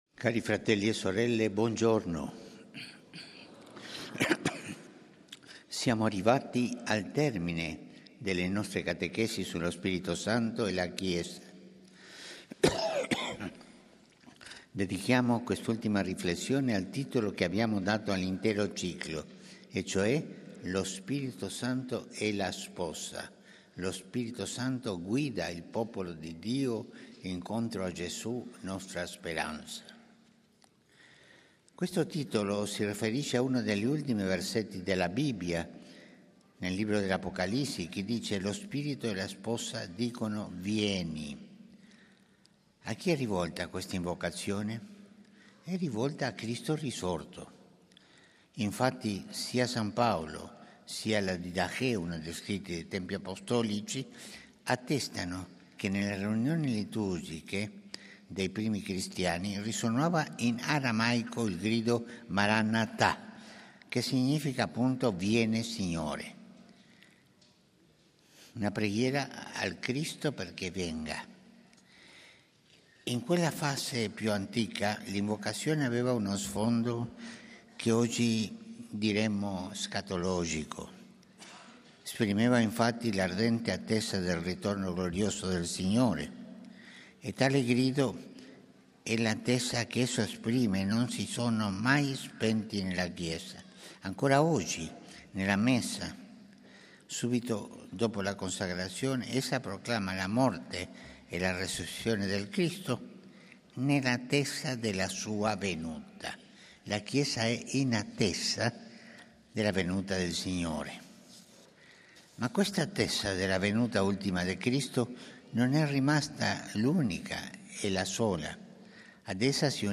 UDIENZA GENERALE di PAPA FRANCESCO
Aula Paolo VIMercoledì, 11 dicembre 2024